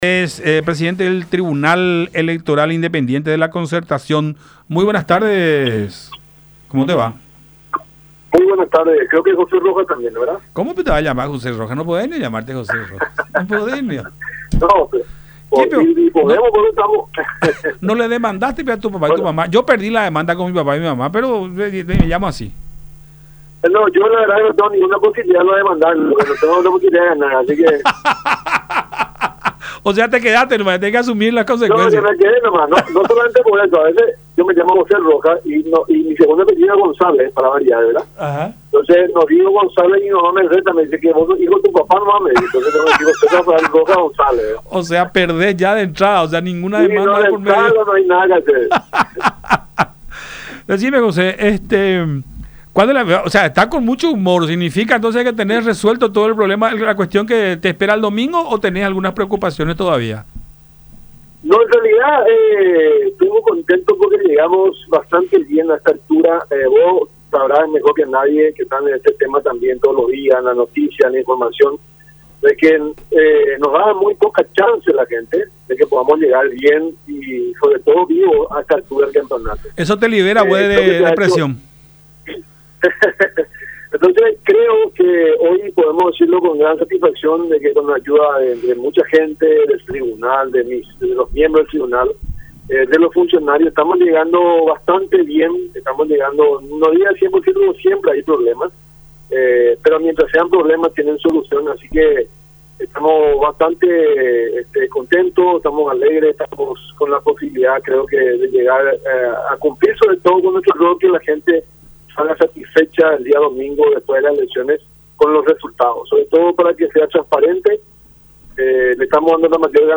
en diálogo con Buenas Tardes La Unión por Unión TV y radio La Unión.